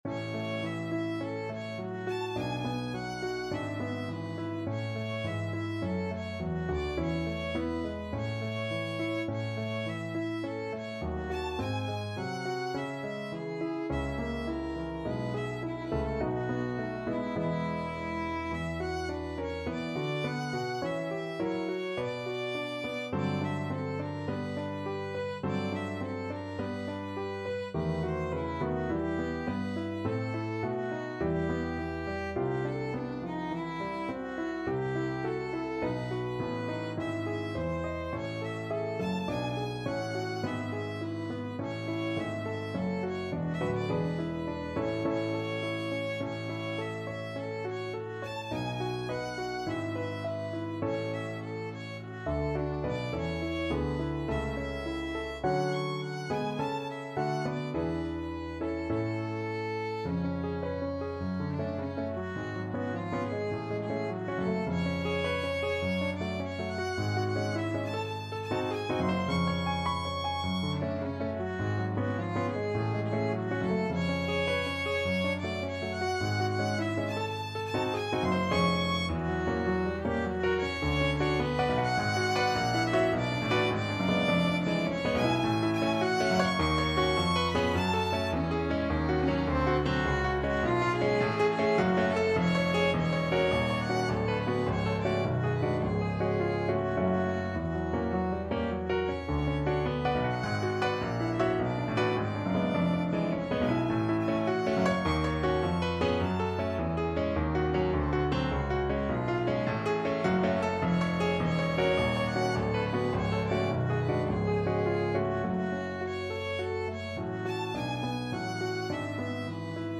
Violin version
Einfach, innig =104
4/4 (View more 4/4 Music)
Classical (View more Classical Violin Music)